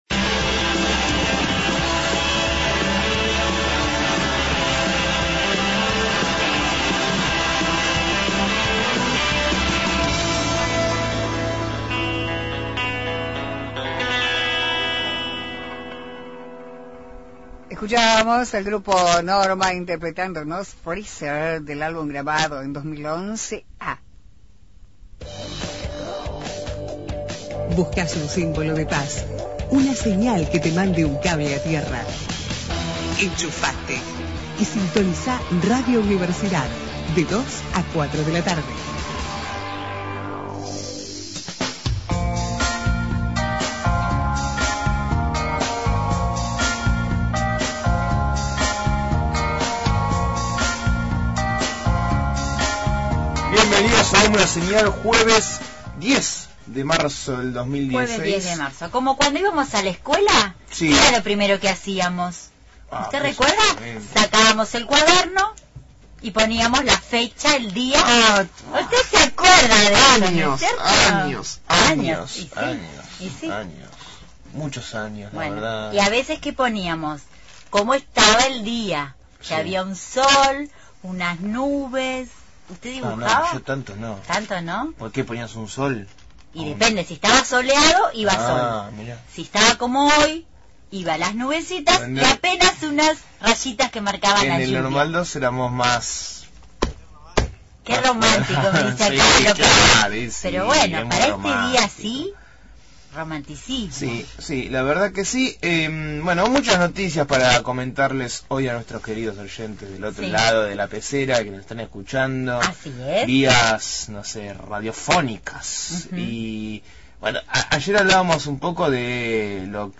MÓVIL/ Situación de los trabajadores de Infojus – Radio Universidad